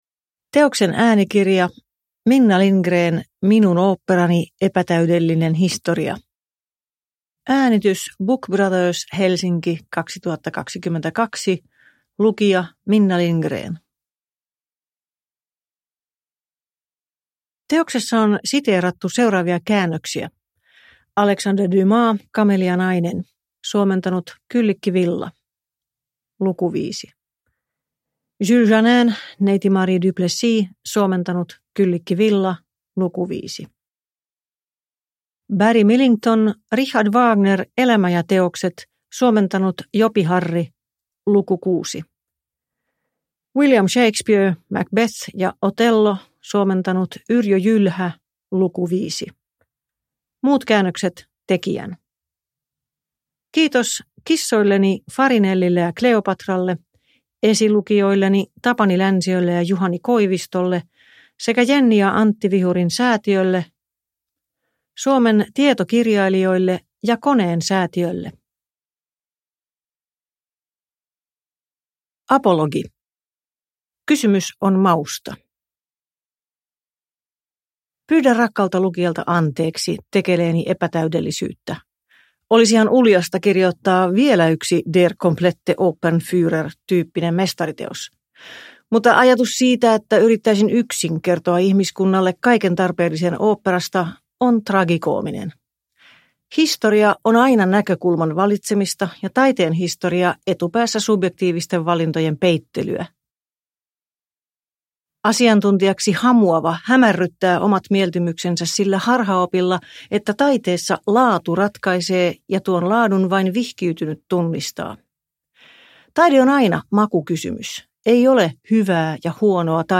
Minun oopperani – Ljudbok – Laddas ner
Uppläsare: Minna Lindgren